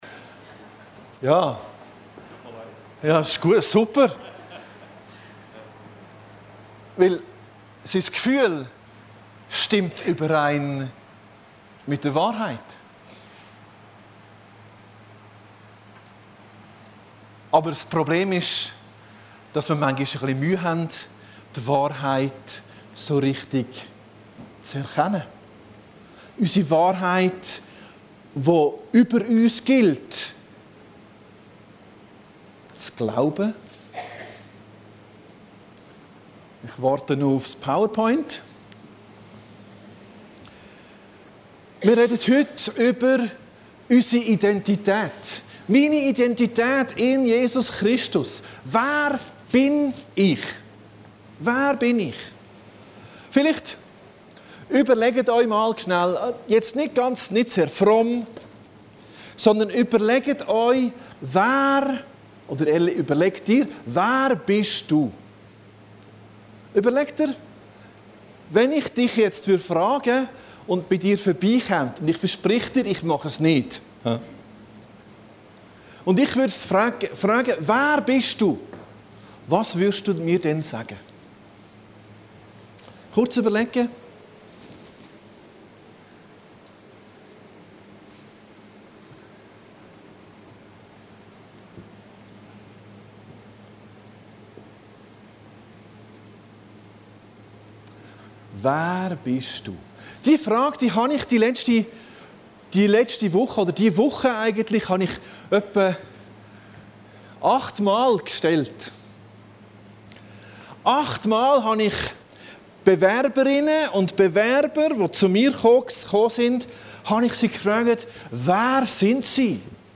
Predigten Heilsarmee Aargau Süd – Meine Identität in Jesus Christus